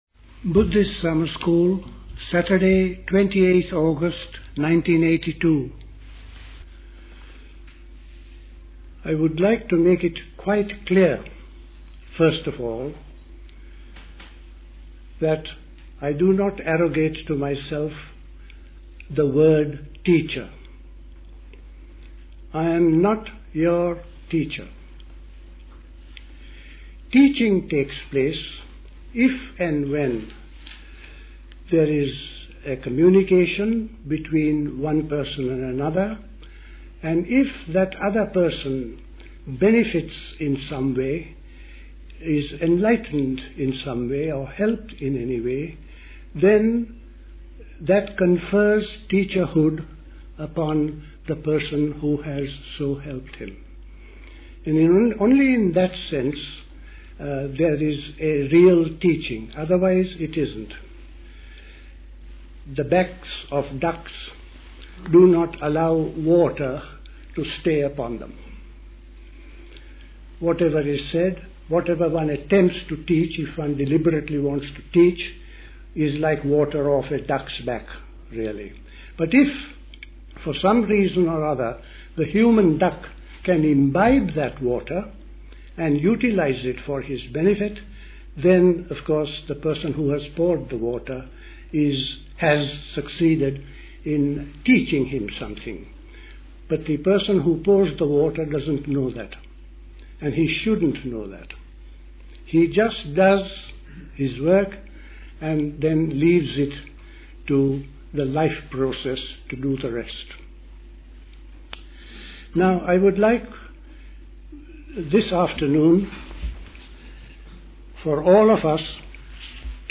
A talk
at High Leigh Conference Centre, Hoddesdon, Hertfordshire